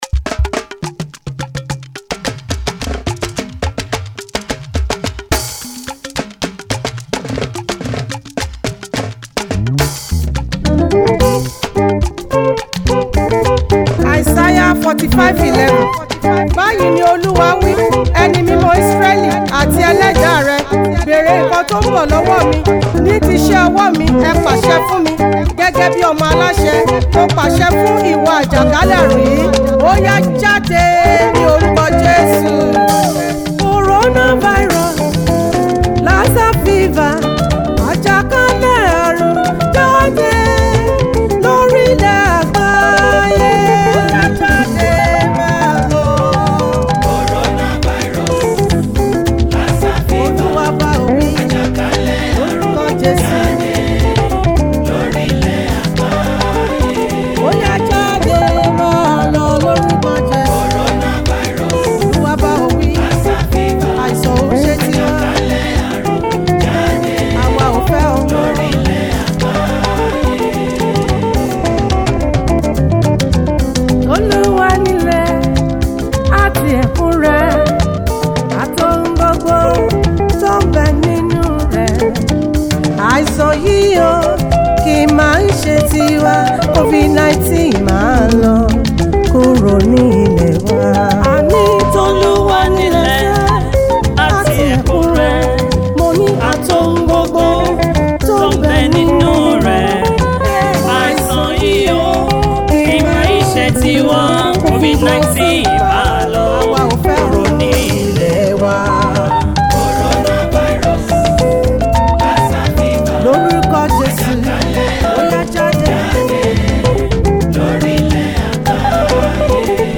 Gospel music
prophetic song